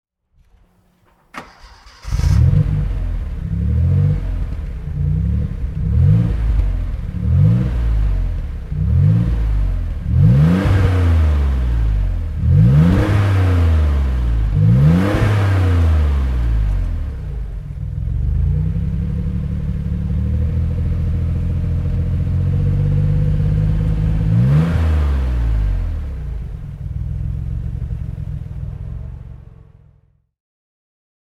Maserati Biturbo 222 E (1990) - Starten und Leerlauf